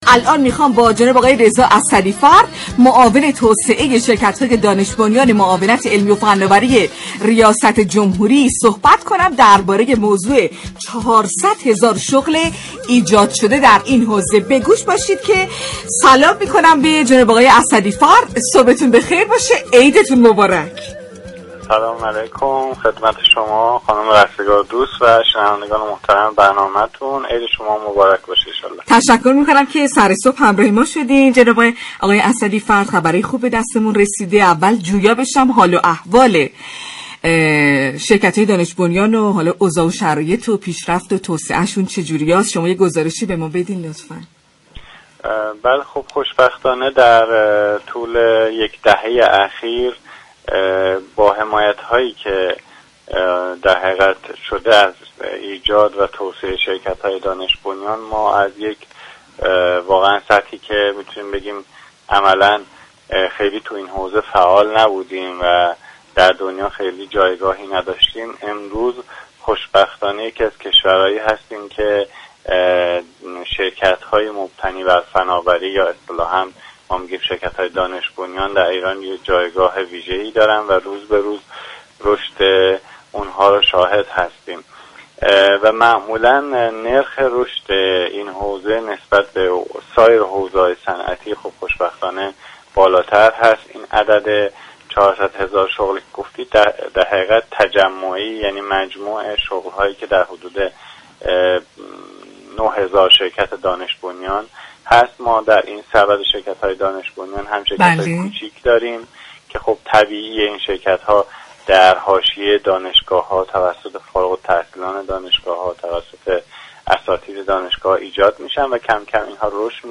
به گزارش پایگاه اطلاع رسانی رادیو تهران، رضا اسدی فرد معاون توسعه شركت‌های دانش بنیان معاونت علمی و فناوری ریاست جمهوری در گفت و گو با «شهر آفتاب» اظهار داشت: شركت‌های دانش بنیان در طول یك دهه اخیر توسعه و رشد خوبی داشته‌اند؛ امروز در جایگاهی ایستاده‌ایم كه شركت‌های مبتنی بر فناوری جایگاه ویژه‌ای دارند.